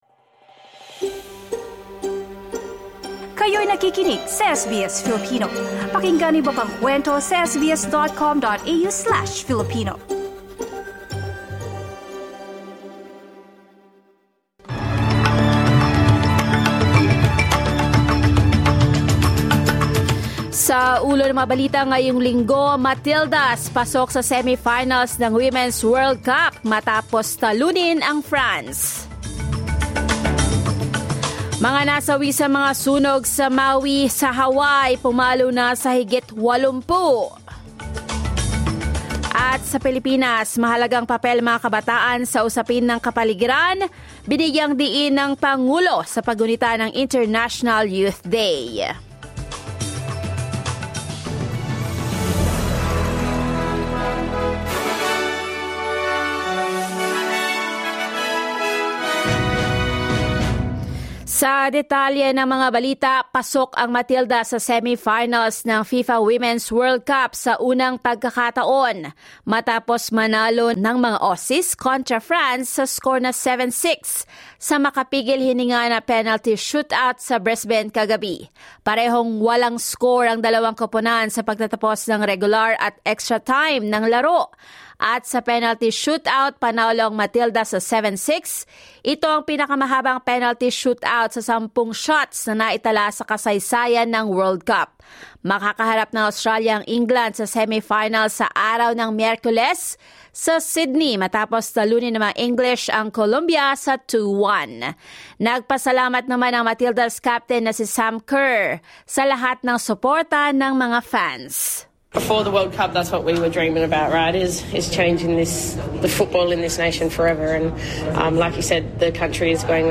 SBS News in Filipino, Sunday 13 August